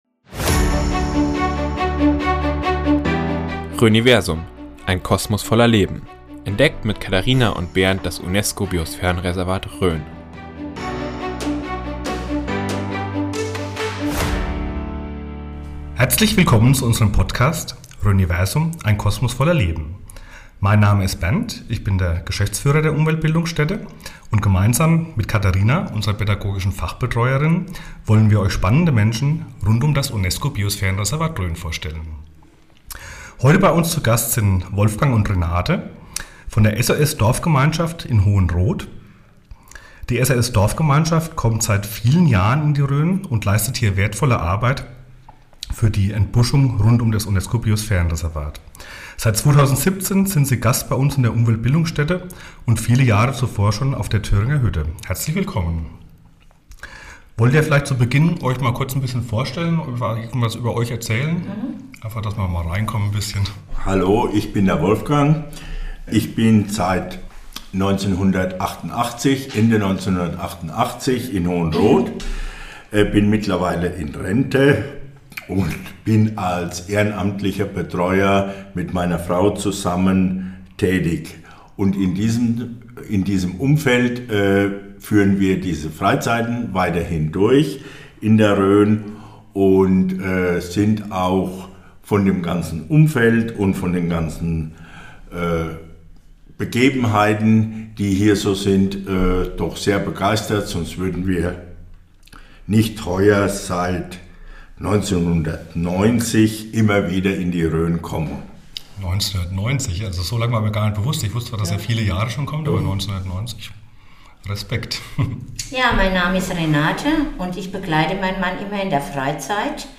Schließlich berichten einige Bewohnerinnen und Bewohner selbst, die bereits seit Jahrzehnten Teil der Dorfgemeinschaft sind, über ihr Leben und ihre Aufgaben in Hohenroth.